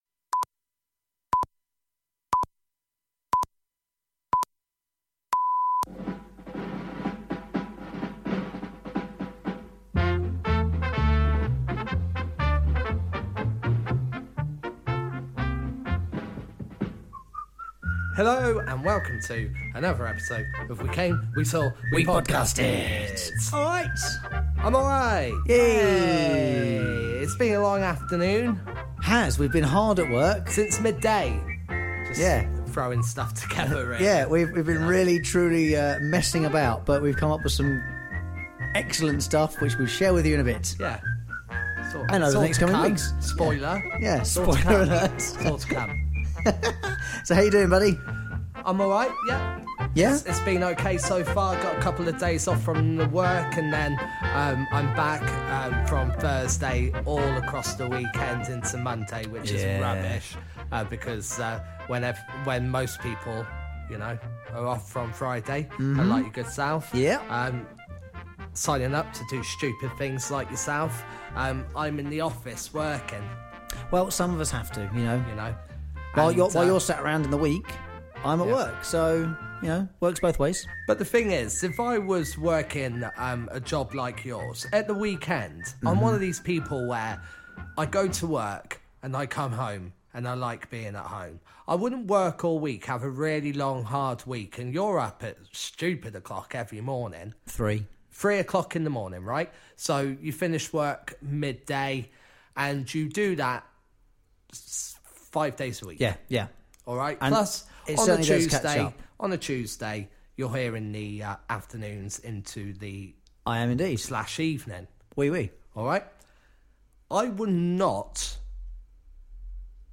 This week episode is quite lively.